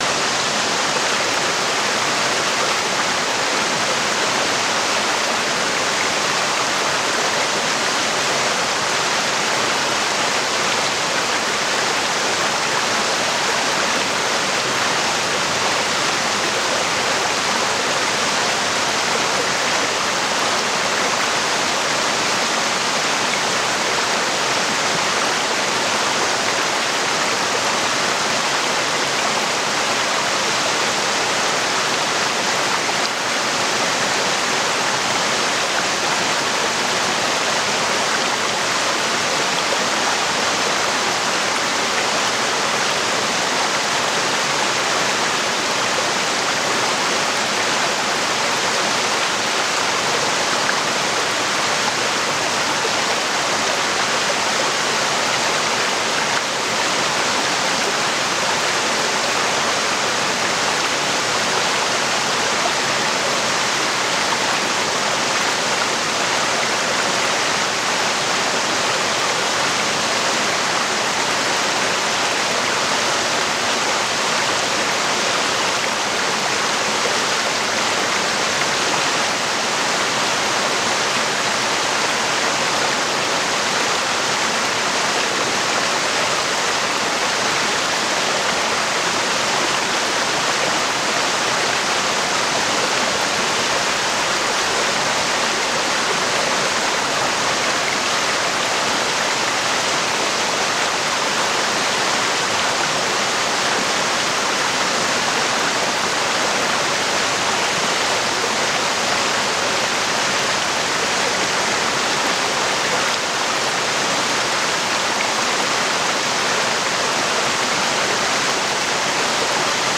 MYSTISCHER NEBEL-ZAUBER: Herbstbach-Zauber mit kristallklarem Rauschen
Naturgeräusche